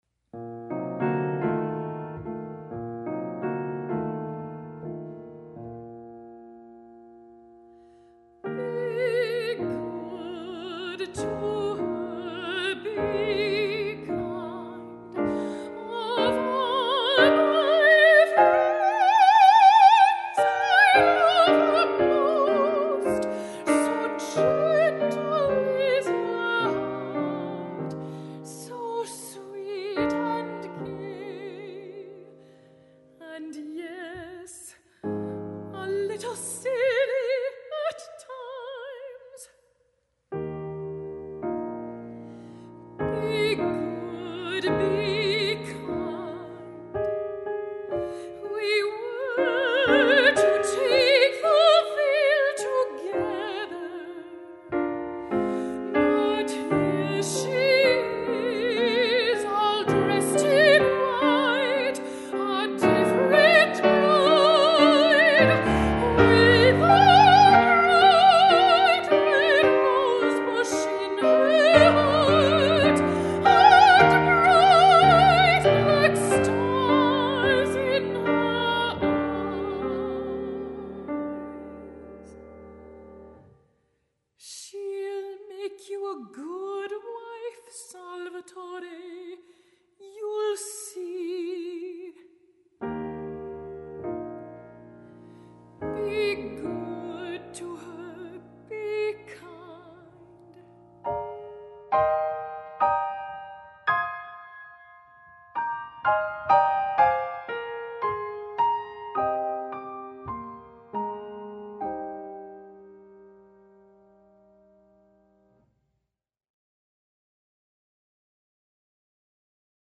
Soprano et Piano